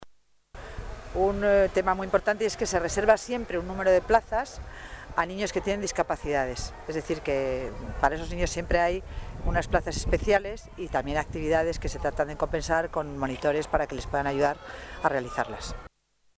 Nueva ventana:La delegada de Familia y Servicios Sociales habla de las plazas para discapacitados